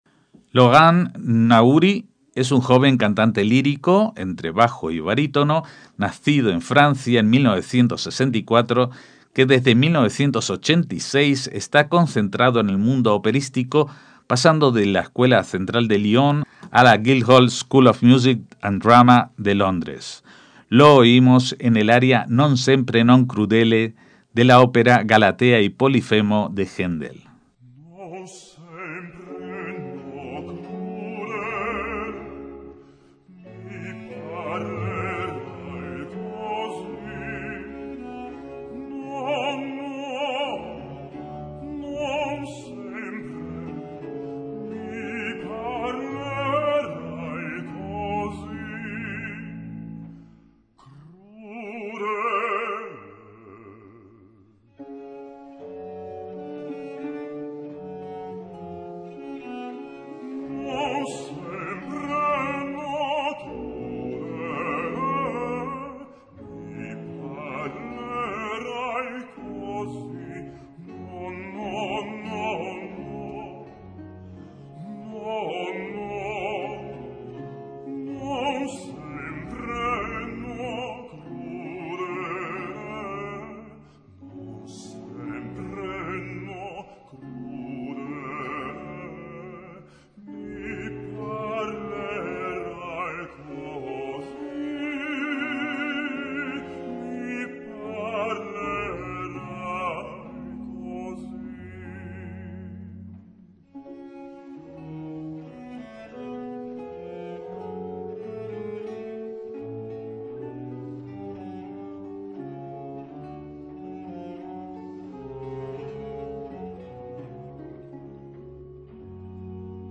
cantante lírico